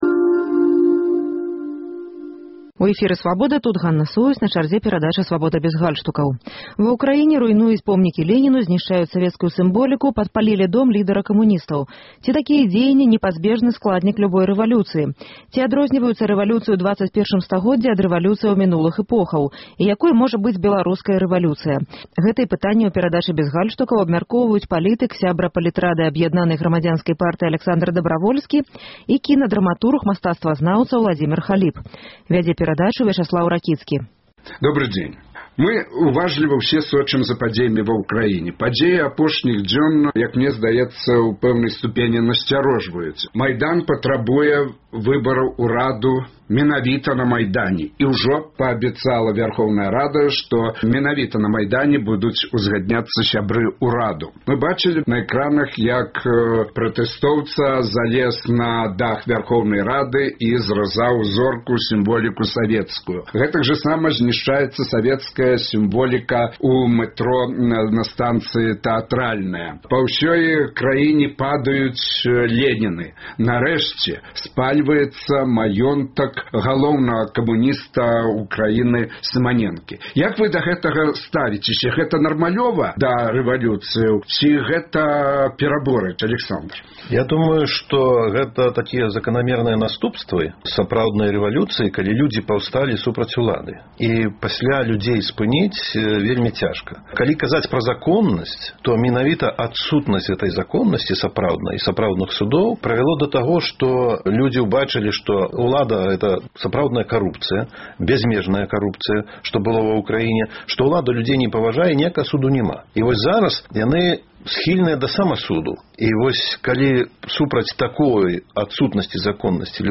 Ці такія дзеяньні — непазьбежны складнік любой рэвалюцыі? Ці адрозьніваюцца рэвалюцыі ў ХХІ стагодзьдзі ад рэвалюцыяў мінулых эпохаў? У дыскусіі бяруць удзел палітык